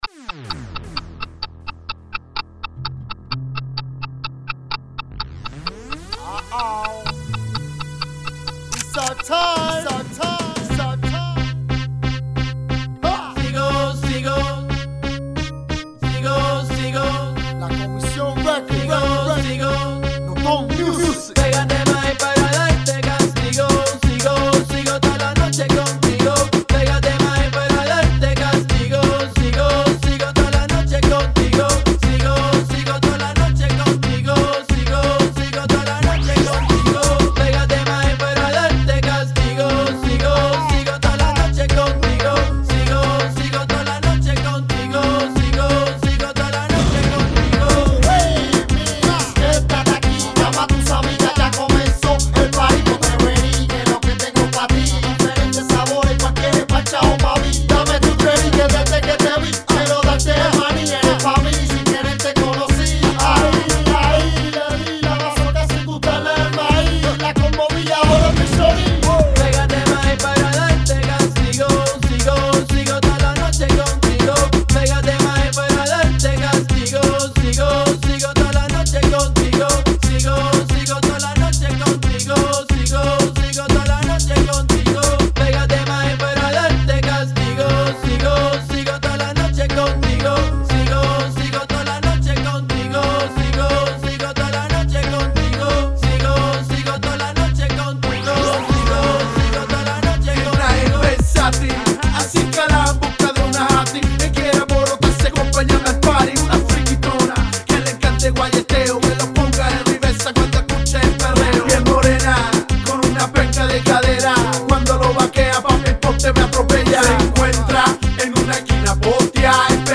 Reggaeton/ Hip Hop genre